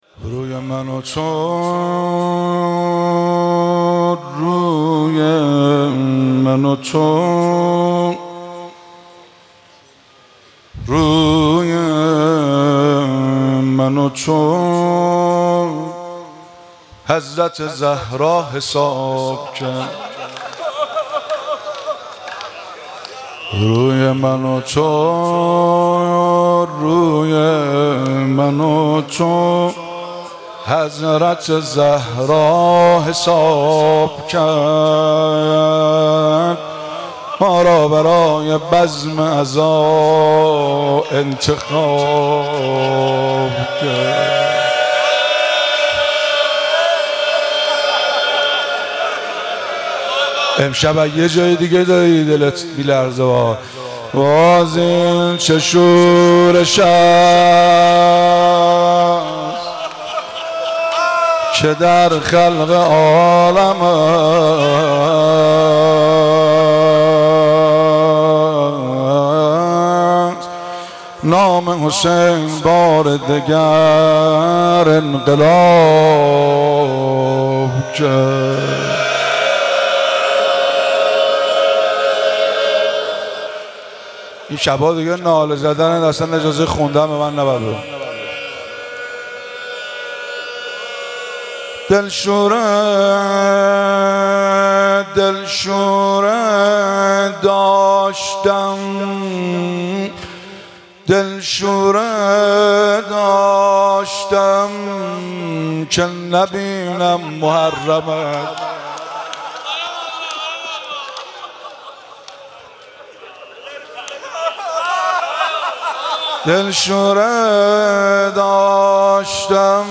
همزمان با برگزاری شب اول محرم در هیئات مذهبی صوت و فیلم برخی از مداحان کشورمان منتشر شد.
فیلم و صوت مداحی حاج مهدی رسولی شب گذشته در شب اول محرم 1444 (7 مردادماه 1401) در هیئت‌ ثاراللّٰه‌ زنجان منتشر شد.